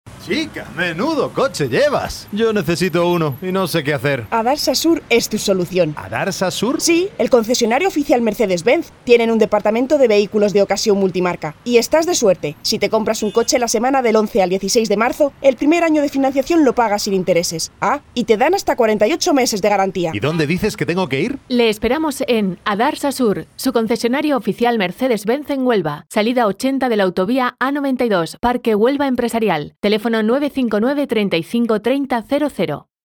kastilisch
Sprechprobe: Werbung (Muttersprache):